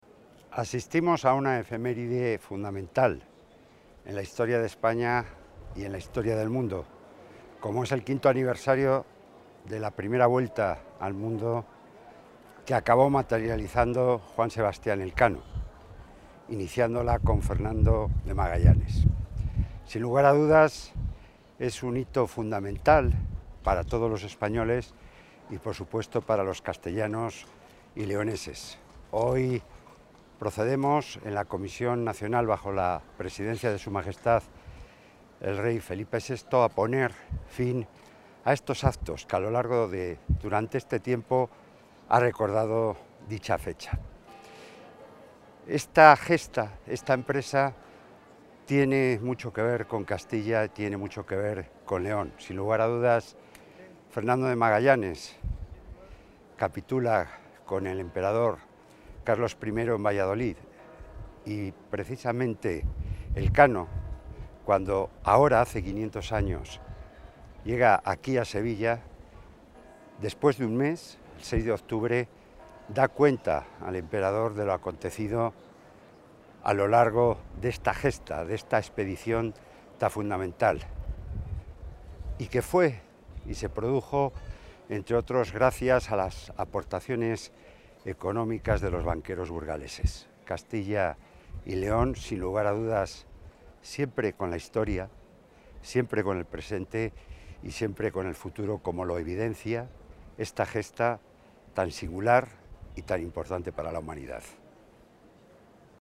Audio consejero.
El consejero de la Presidencia, Jesús Julio Carnero, ha participado esta tarde, en el Real Alcázar de Sevilla, en los actos conmemorativos del V Centenario de la expedición de la primera vuelta al mundo de Fernando de Magallanes y Juan Sebastián Elcano bajo la presidencia del Rey Felipe VI.